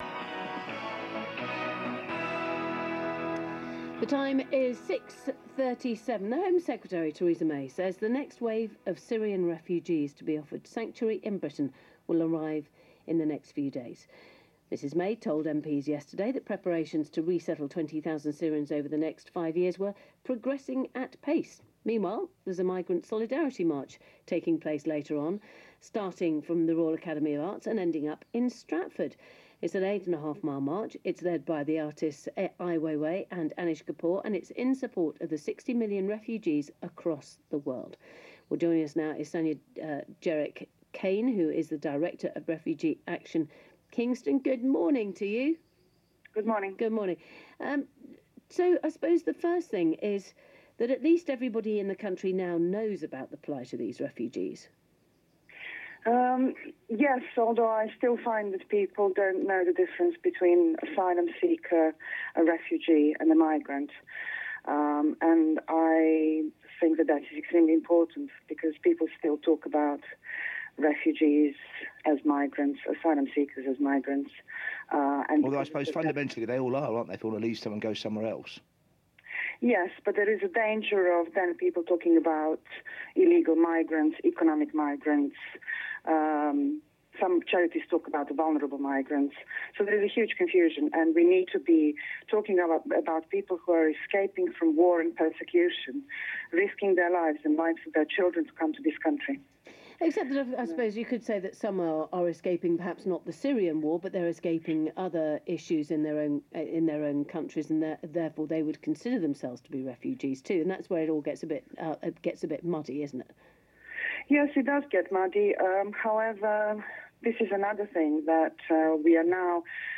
speaking on BBC Radio